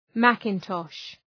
Προφορά
{‘mækın,tɒʃ}